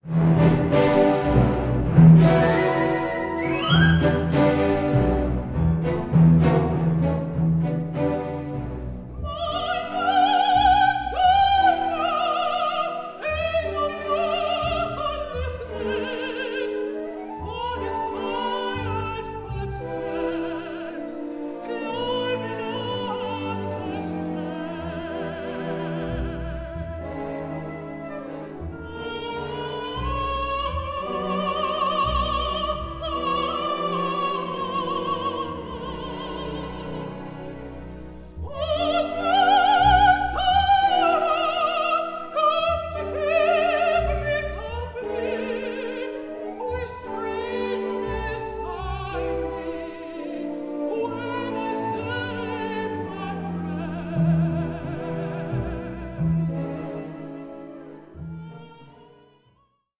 THE ULTIMATE AMERICAN “FOLK OPERA”
a live recording